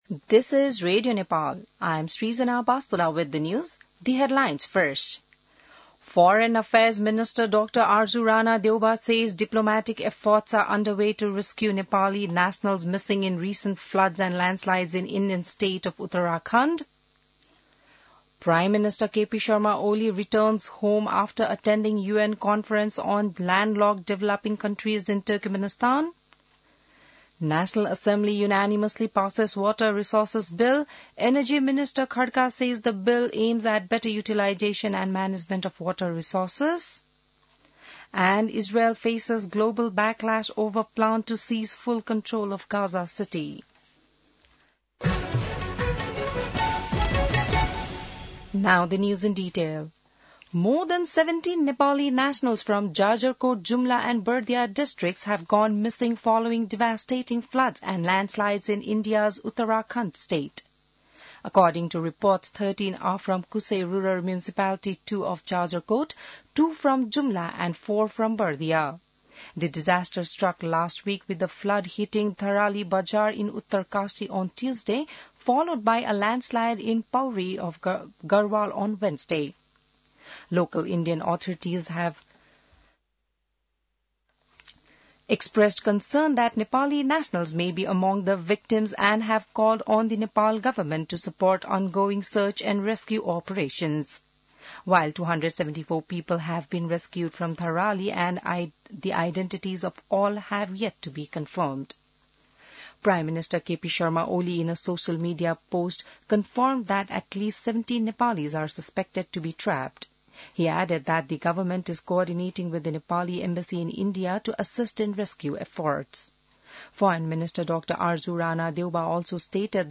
बिहान ८ बजेको अङ्ग्रेजी समाचार : २४ साउन , २०८२